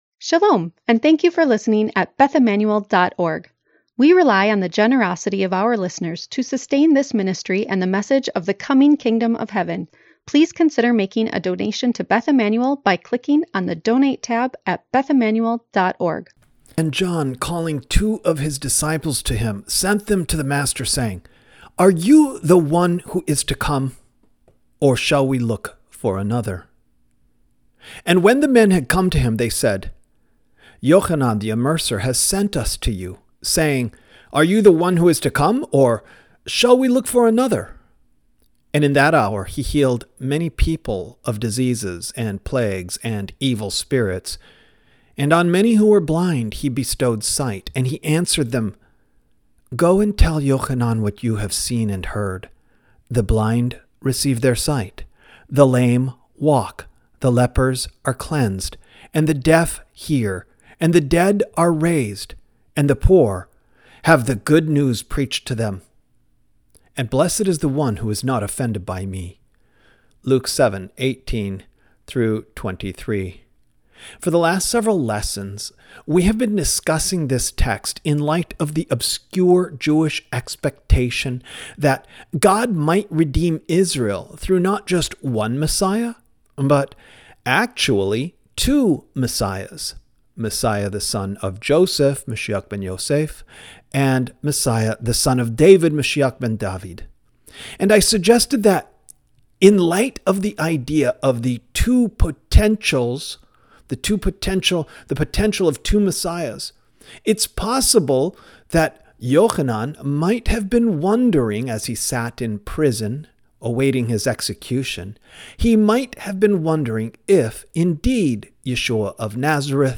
This feed offers Messianic Jewish audio teachings, including Torah commentaries and concepts in Messianic Judaism.